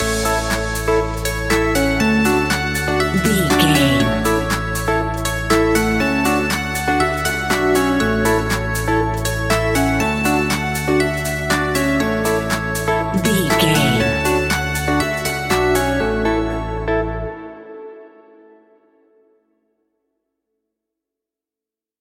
Ionian/Major
smooth
electric guitar
bass guitar
drum machine
synthesiser
funky house
deep house
nu disco
groovy
clavinet
fender rhodes
horns